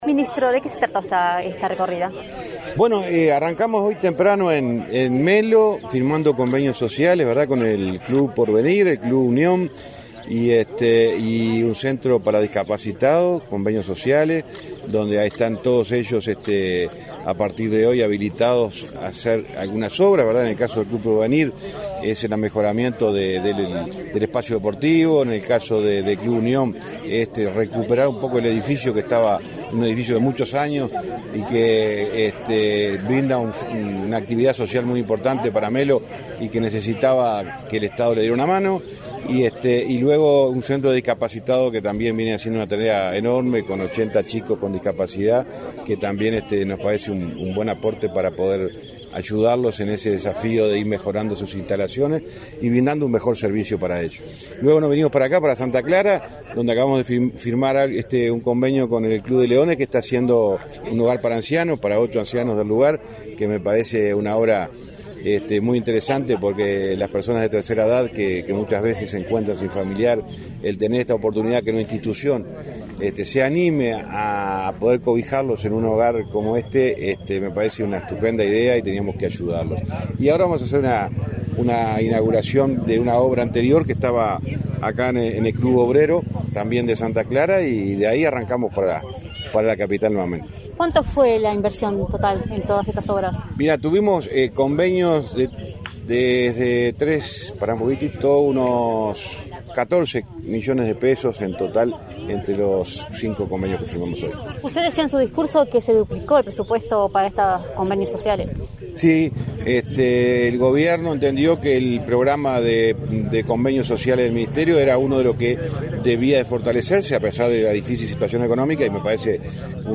Declaraciones del ministro de José Luis Falero, ministro de Transporte y Obras Públicas
En la oportunidad firmó convenios sociales con ambas intendencias departamentales. Tras los actos, efectuó declaraciones a Comunicación Presidencial.